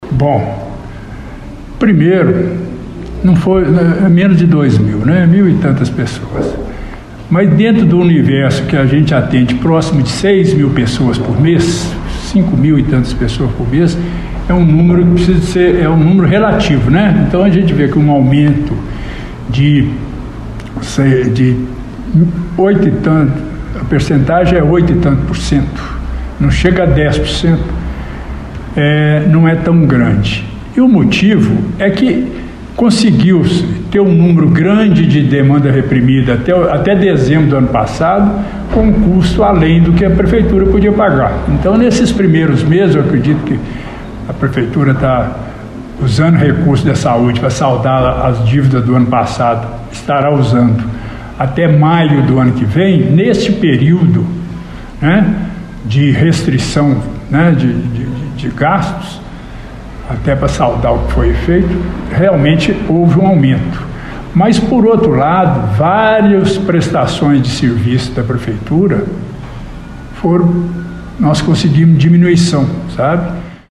De janeiro a julho deste ano, a fila de espera para atendimento médico aumentou em mais de 2 mil pacientes, se comparado com o mesmo período do ano anterior. Os números foram apresentados em um questionamento do presidente da Comissão de Saúde, Cristiano Fernandes, na reunião da Câmara Municipal do dia 23 de setembro.
A fala do parlamentar foi contra argumentada pelo secretário Municipal de Saúde, Gilberto Denoziro que levantou alguns pontos da pasta: